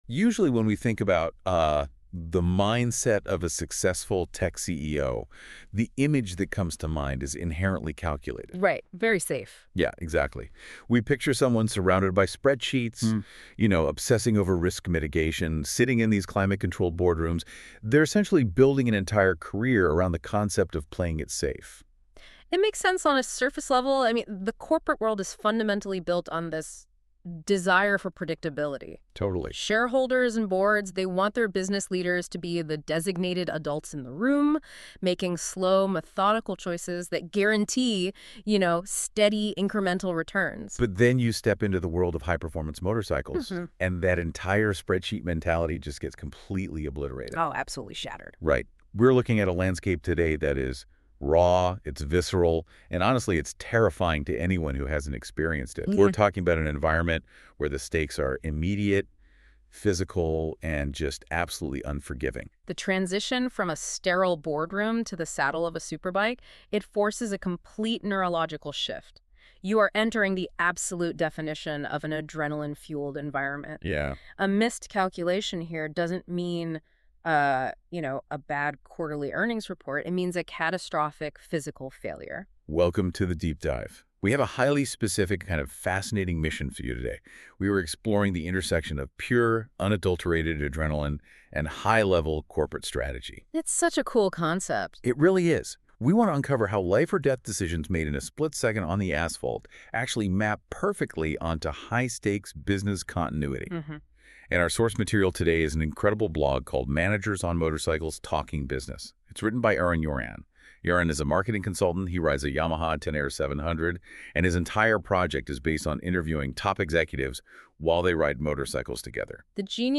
רכיבה ושיחה